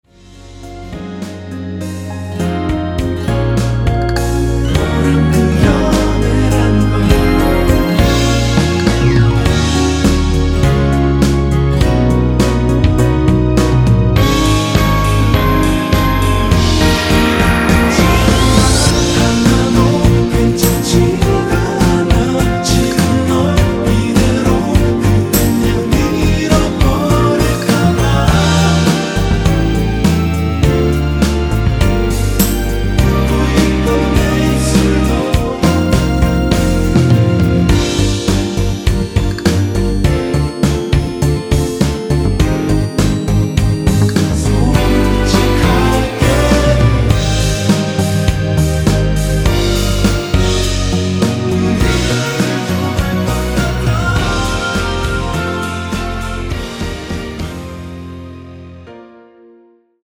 원키에서(-1)내린 코러스 포함된 MR입니다.
Db
앞부분30초, 뒷부분30초씩 편집해서 올려 드리고 있습니다.